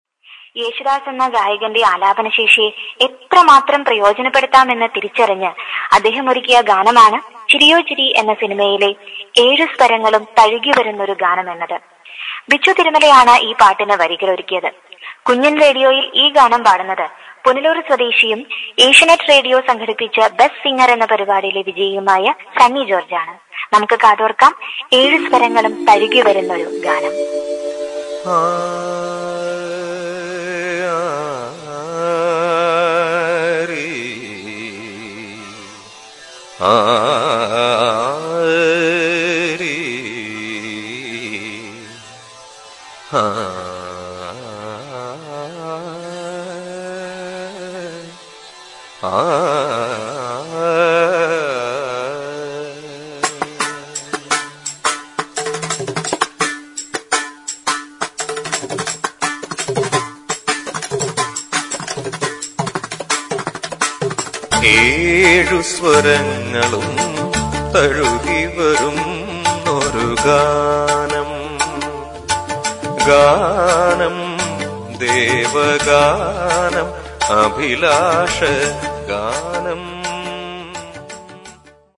രണ്ടാമത് അറ്റാച്ച് ചെയ്തിരിക്കുന്നത് ഒരു അനൗൺസ്മെന്റ് സാമ്പിളും..
Ganamela-Episode1-Ad.mp3